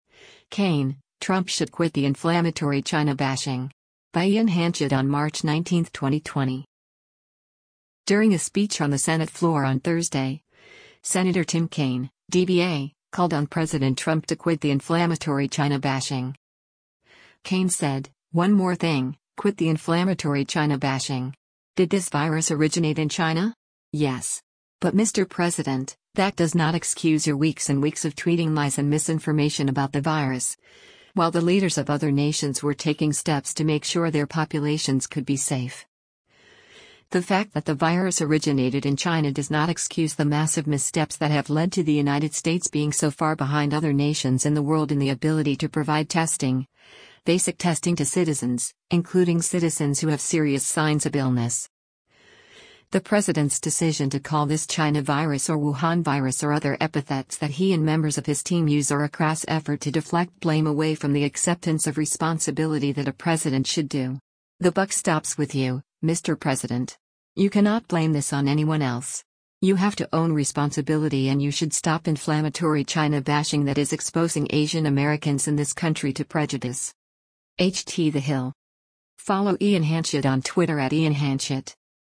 During a speech on the Senate floor on Thursday, Sen. Tim Kaine (D-VA) called on President Trump to “quit the inflammatory China-bashing.”